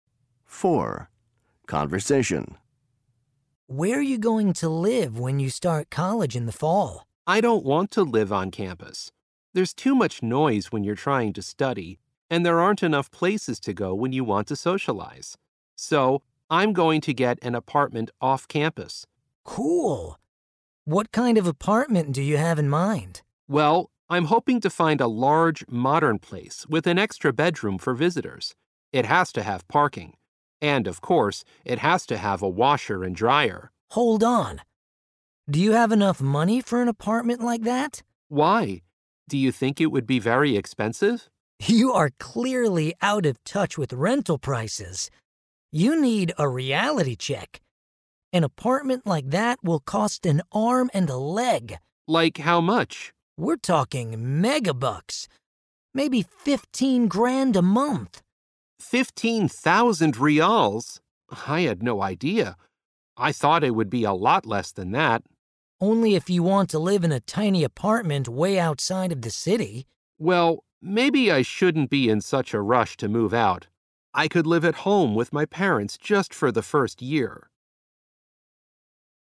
صوتيات للدروس 4 conversation mp3